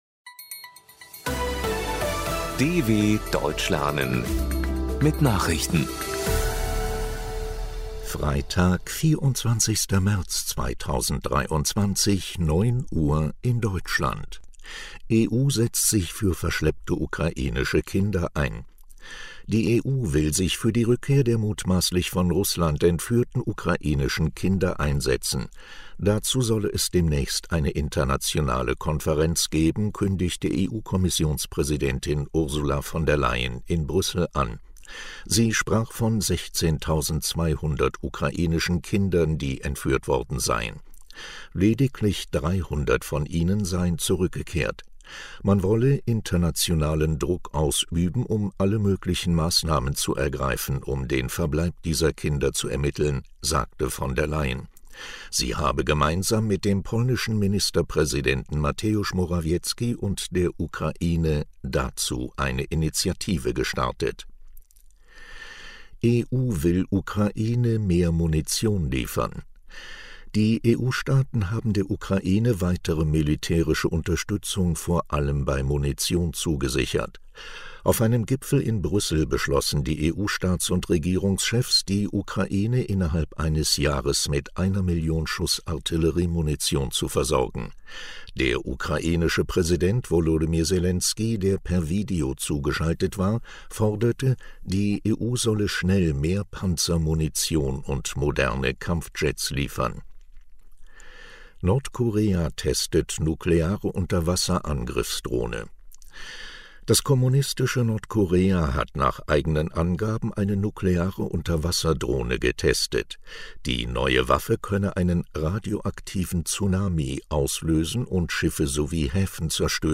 Langsam gesprochene Nachrichten
Trainiere dein Hörverstehen mit den Nachrichten der Deutschen Welle von Freitag – als Text und als verständlich gesprochene Audio-Datei.
Nachrichten von Freitag, 24.03.2023 – langsam gesprochen | MP3 | 6MB